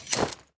unequip_wolf1.ogg